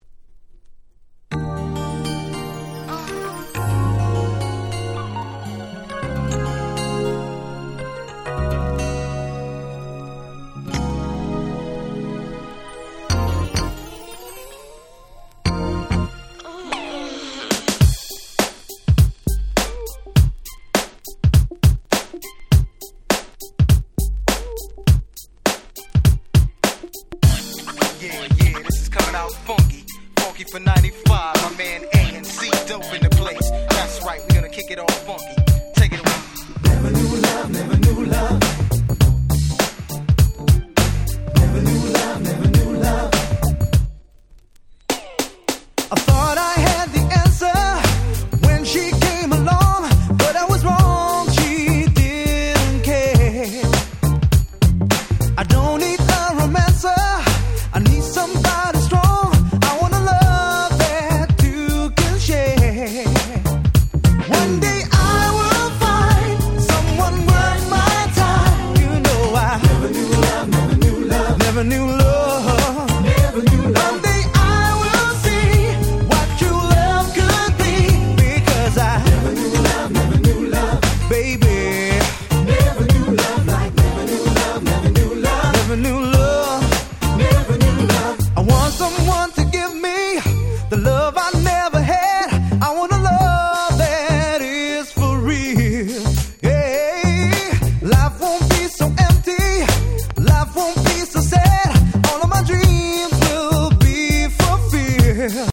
96' Very Nice UK R&B !!
UK3人組男性Vocalユニット
UK Soul キャッチー系